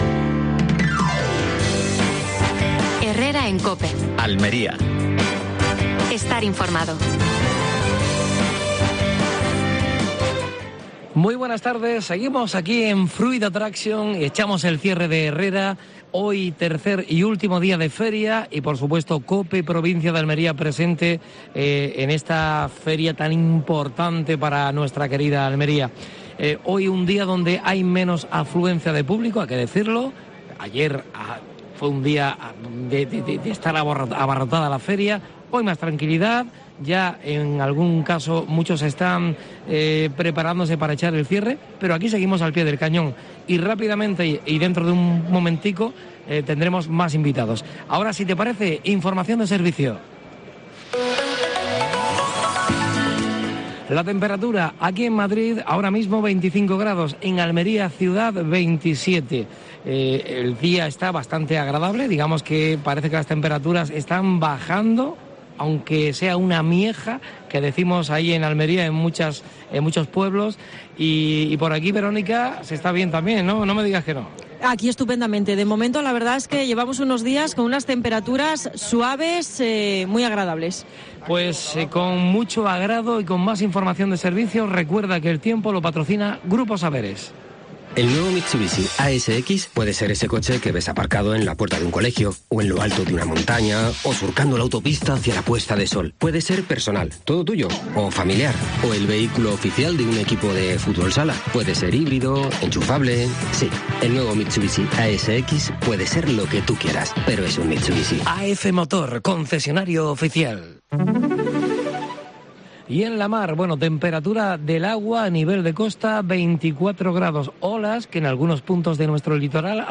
AUDIO: Programa especial desde Fruit Attraction (Madrid). Entrevista